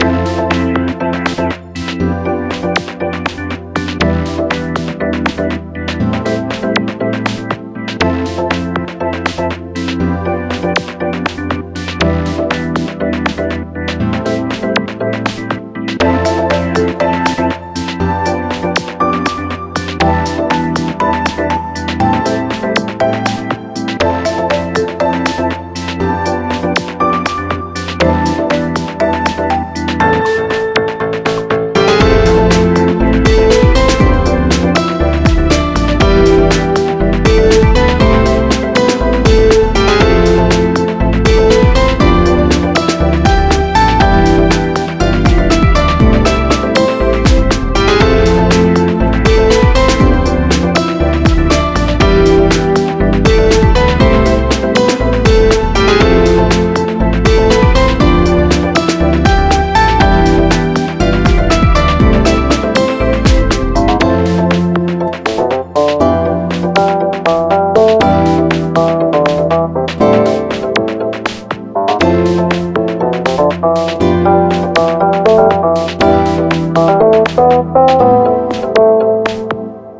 Soundtrack　 BPM:120　 UNSET
ループ OP エモ ノスタルジック